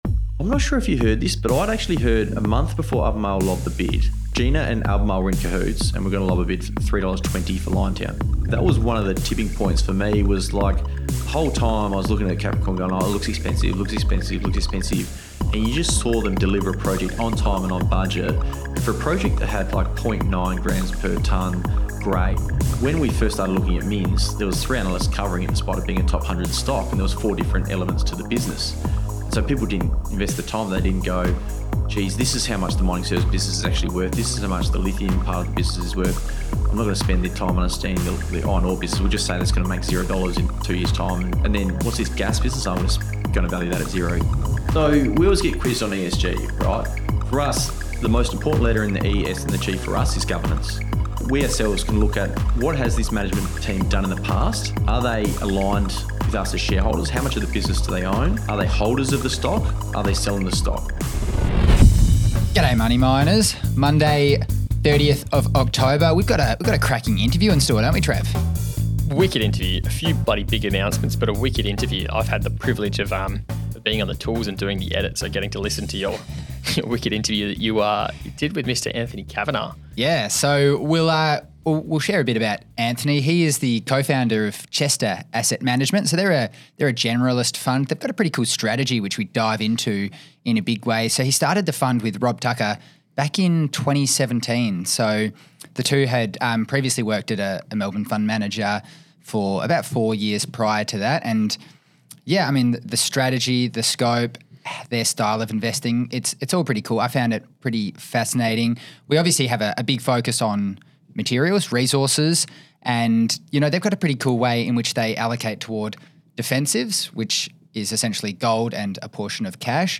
in a jam-packed discussion that we’ll release in 2 parts.